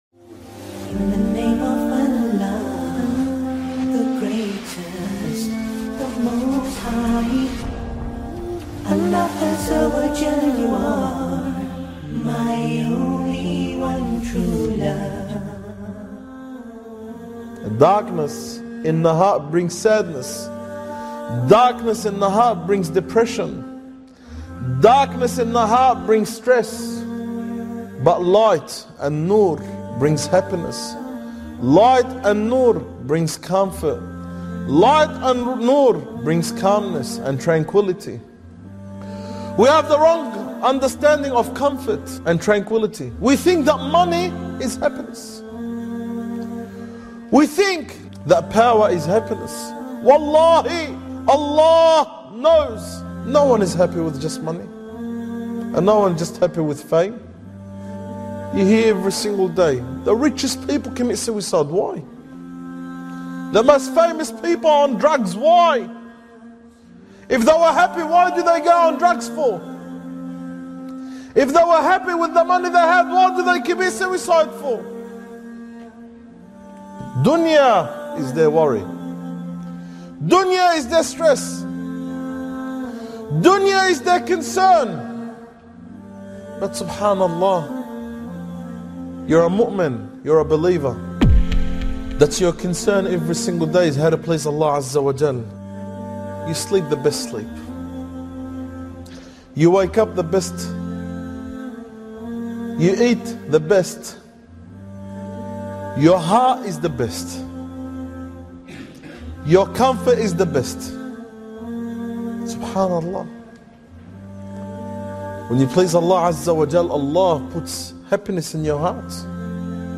Allah Cures the Hearts! Emotional Reminder